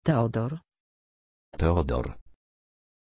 Pronuncia di Teodor
pronunciation-teodor-pl.mp3